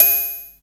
SPACEBONK.wav